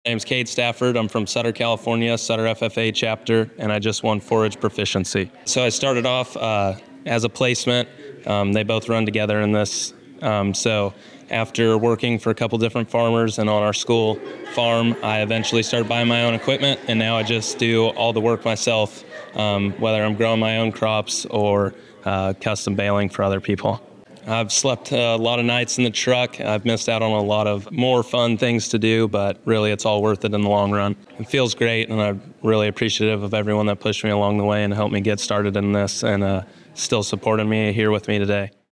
By Published On: October 25th, 20240.4 min readCategories: Convention Audio